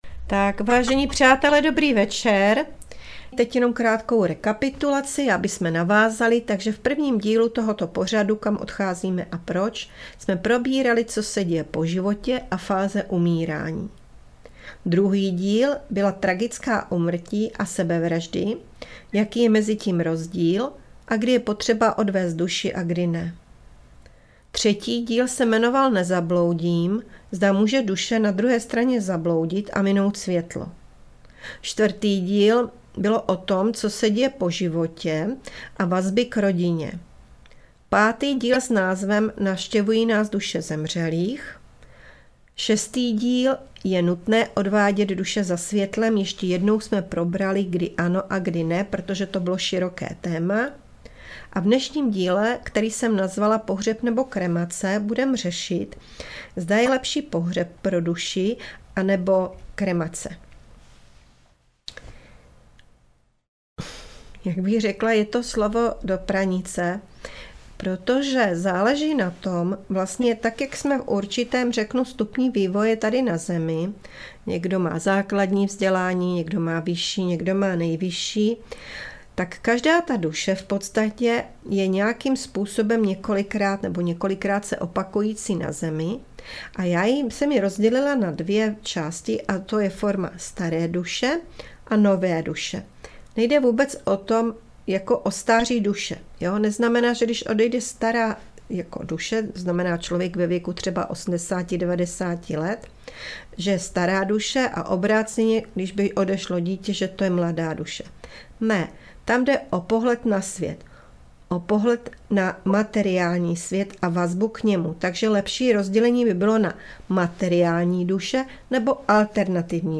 Přednáška Duše zemřelých, díl 7. - Pohřeb nebo kremace?